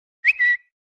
Darmowe dzwonki - kategoria SMS
Dzwonek - Ćwierkanie ptaszka
Dzwięk przypominający śpiew ptaszka. Cichy dzwięk, idealnie nadaje się dla spokojnej osoby.
cwierkanie-ptaszka.mp3